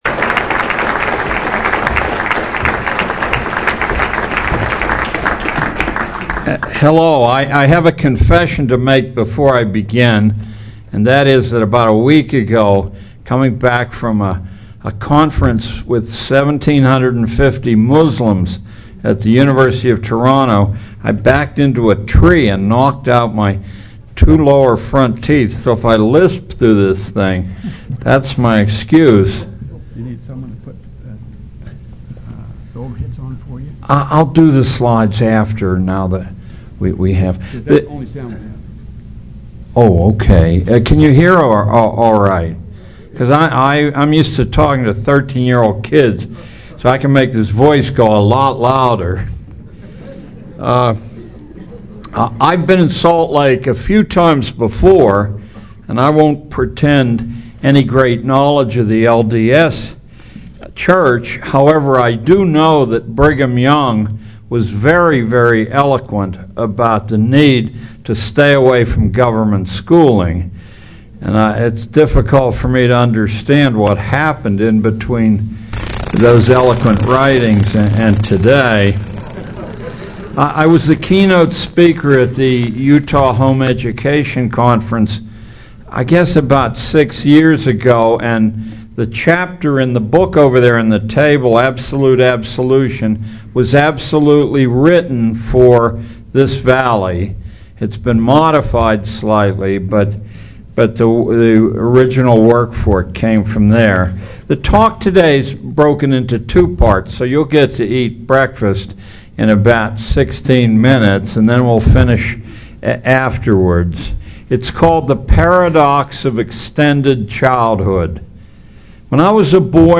It was apparently given in 2001 to the LDS Homeschooler’s Association.